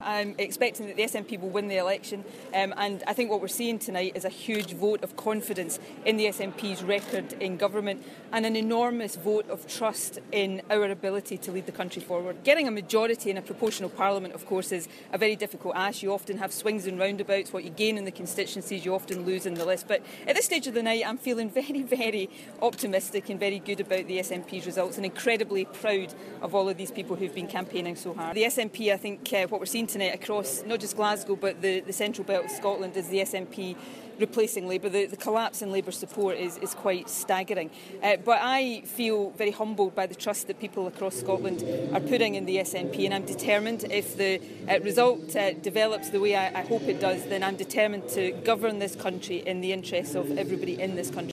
Nicola Sturgeon arrives at the Emirates Arena in Glasgow - Election Live